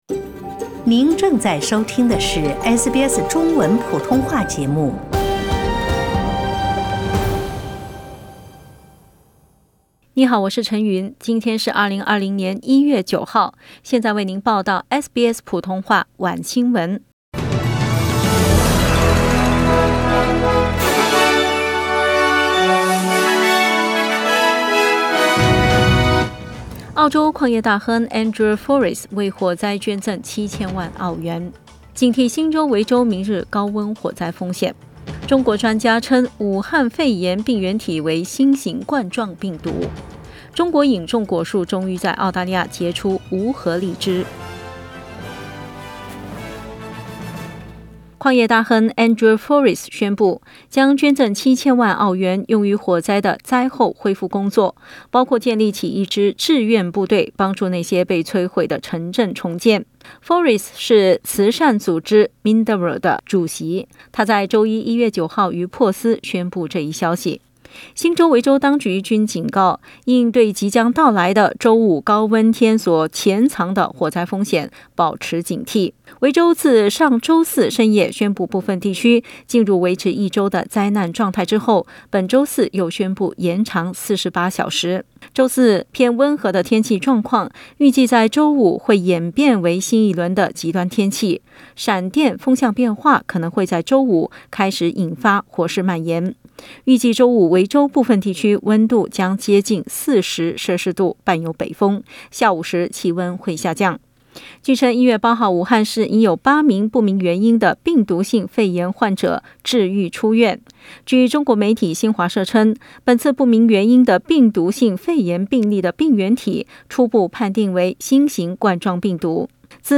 SBS晚新闻(1月9日)